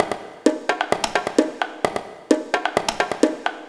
130_bongo_3.wav